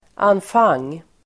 Ladda ner uttalet
anfang substantiv, initial (letter) Uttal: [anf'ang] Böjningar: anfangen, anfanger Definition: större utsirad bokstav som inleder ett kapitel (a large, often ornamental, letter used to begin a chapter)